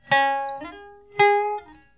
a- upward glissando from the lower to higher notes
b- downward glissando from higher to lower notes.